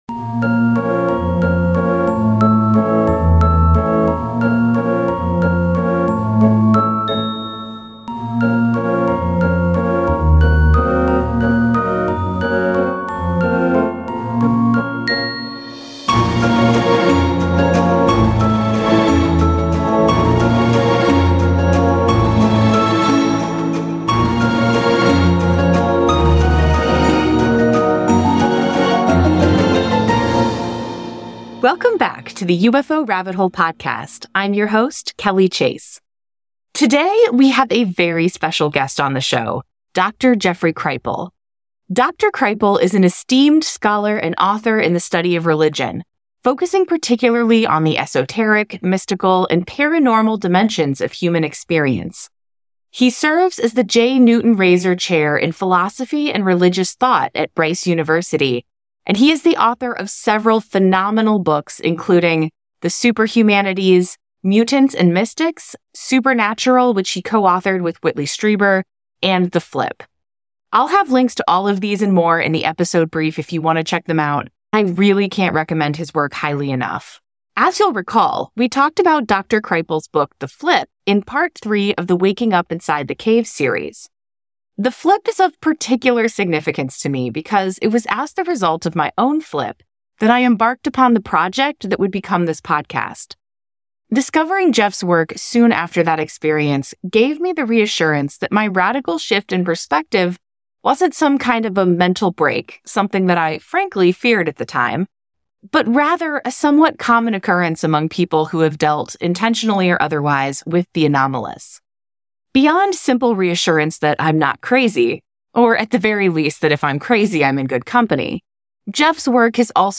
Social Sciences, Society & Culture, Science, Documentary